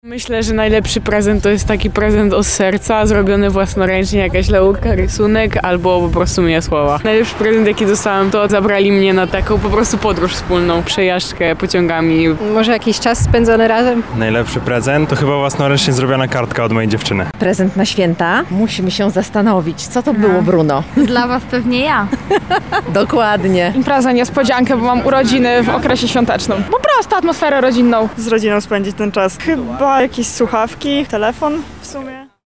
[SONDA] Lublin w świątecznym blasku. Mieszkańcy wspominają najlepsze mikołajkowe prezenty
O tym jakie prezenty sprawiły im największą radość dzielą się mieszkańcy Lublina:
sonda mikołajki